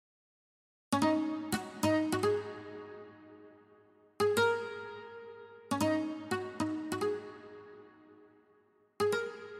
Tag: 100 bpm Fusion Loops Guitar Acoustic Loops 1.62 MB wav Key : A Reason